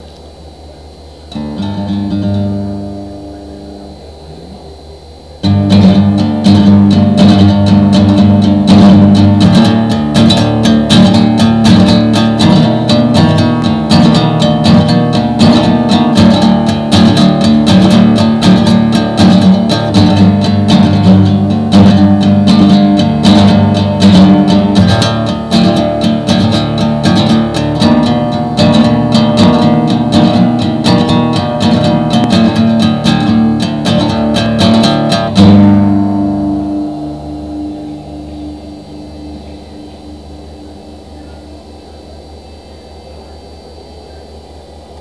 another acoustic rambling